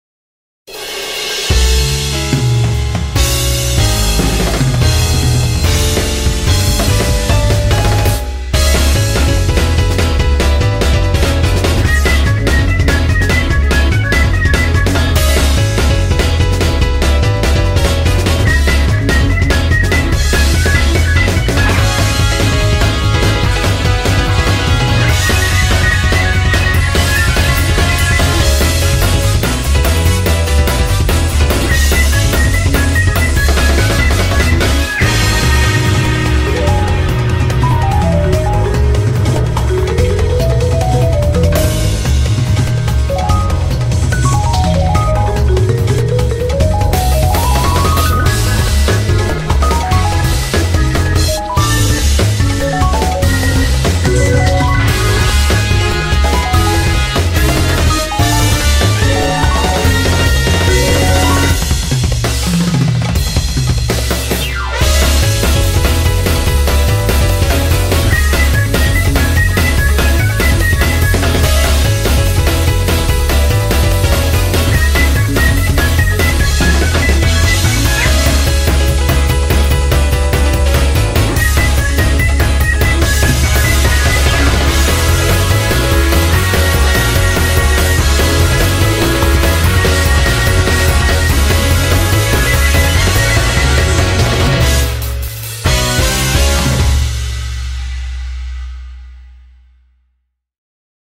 BPM73-290